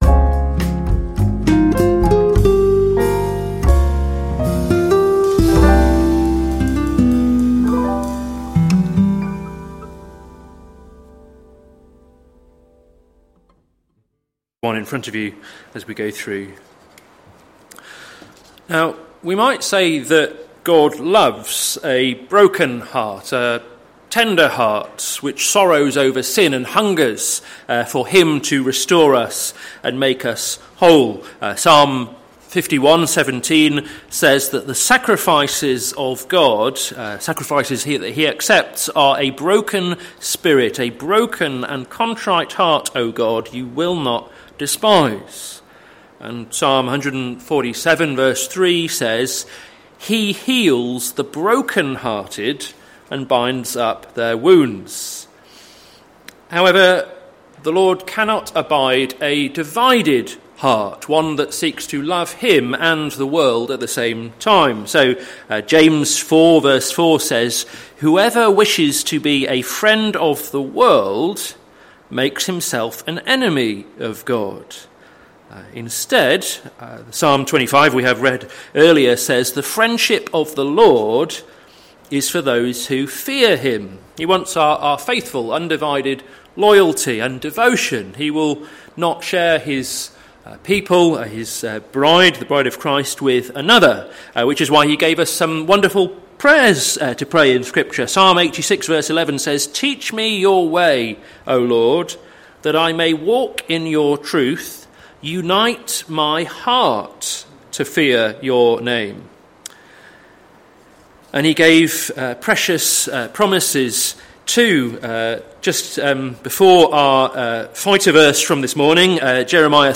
Sermon Series - Caught in Two Minds - plfc (Pound Lane Free Church, Isleham, Cambridgeshire)